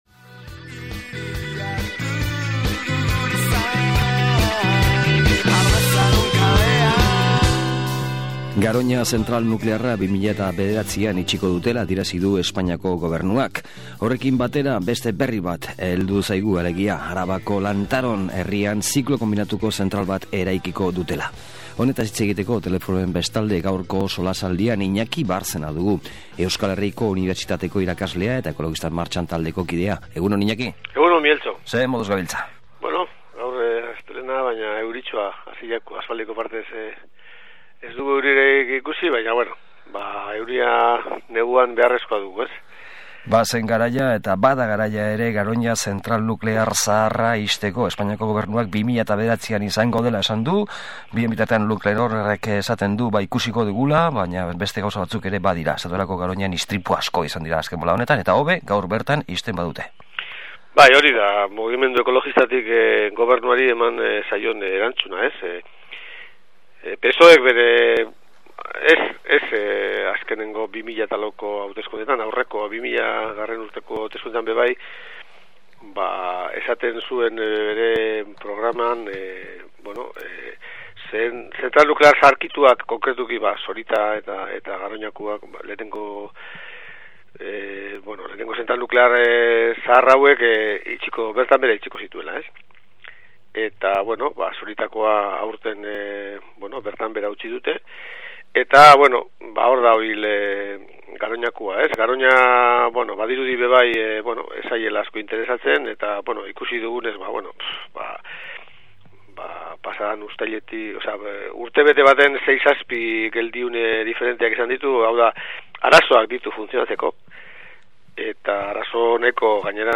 SOLASALDIA: Garoña, Lantaron, etab.